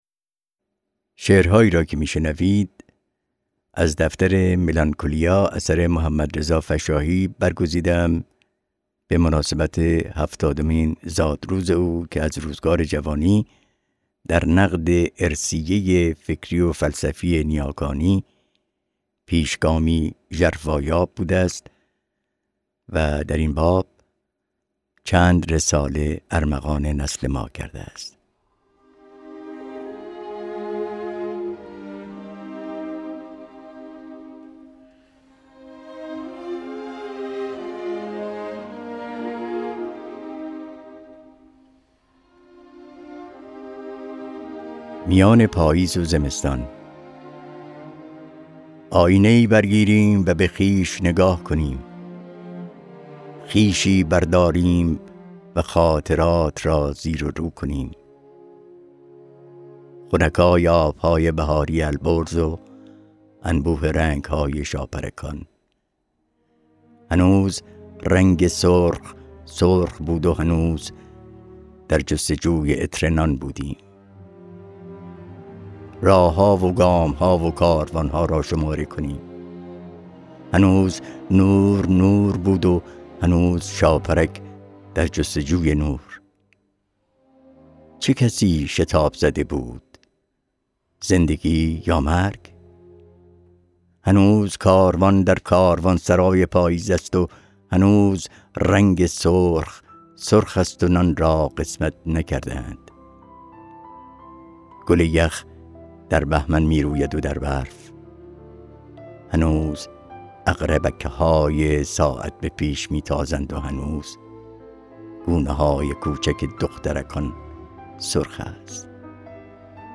باز خوانی چند شعر